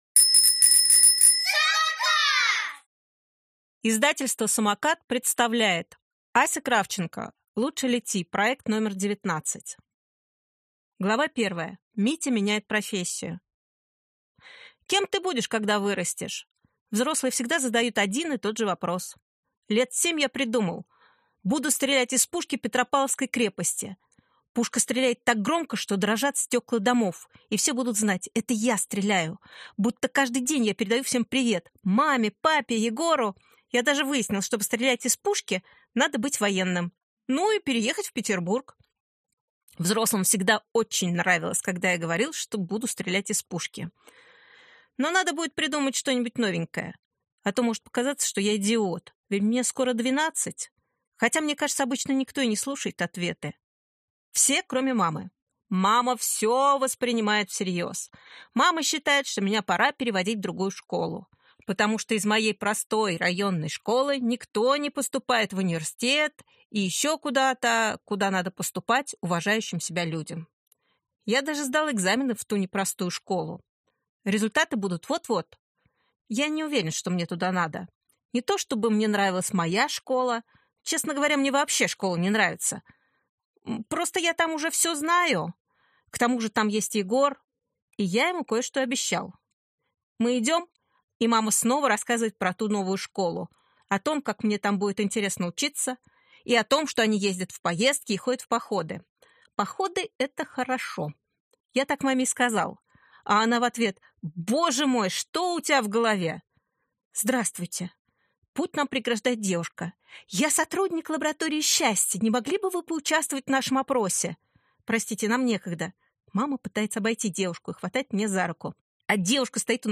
Аудиокнига Лучше лети. Проект № 19. Небо – для всех | Библиотека аудиокниг